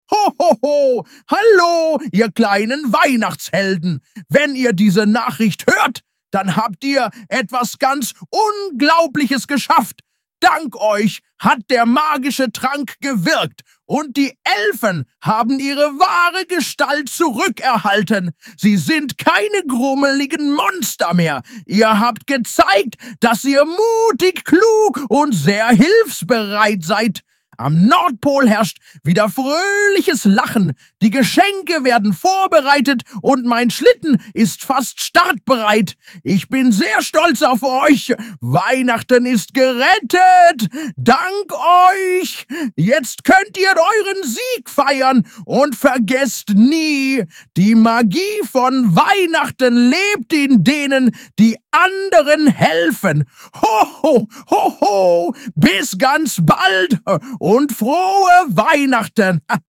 Es klingelt! Hört euch die Nachricht vom Weihnachtsmann an 🎄